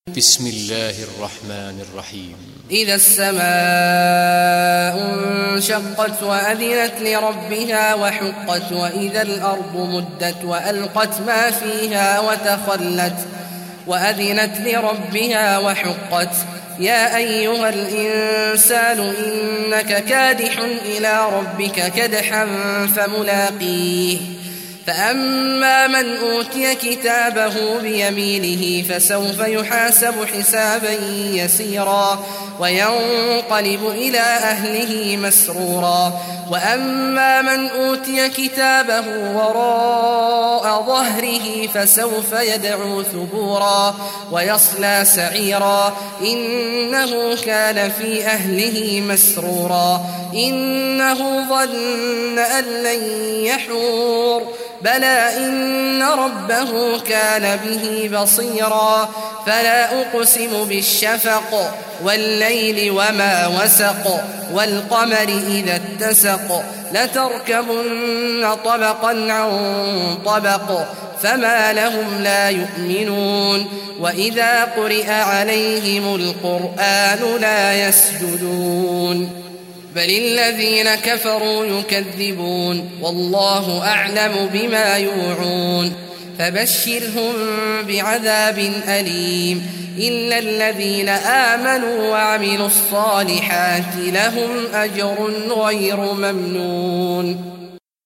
Surah Inshiqaq Recitation by Sheikh Awad Juhany
Surah Inshiqaq, listen or play online mp3 tilawat / recitation in Arabic in the beautiful voice of Sheikh Abdullah Awad al Juhany.